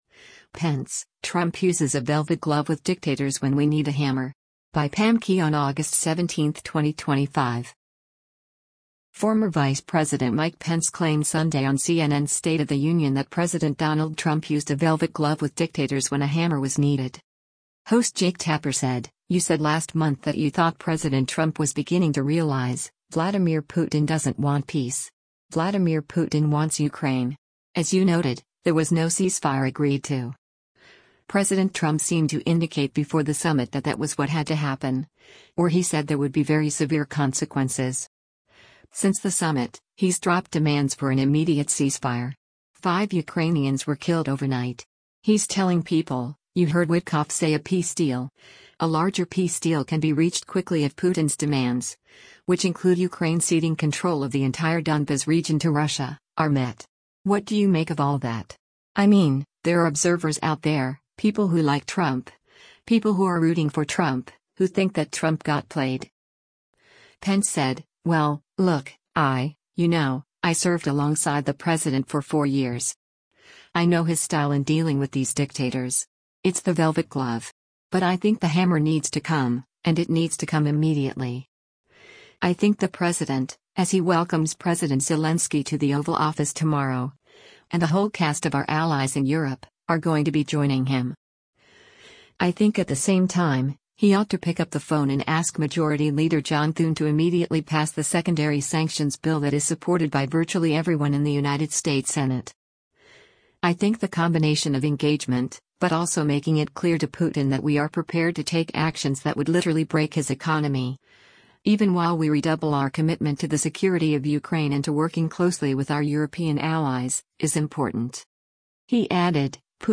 Former Vice President Mike Pence claimed Sunday on CNN’s “State of the Union” that President Donald Trump used a “velvet glove” with dictators when a “hammer” was needed.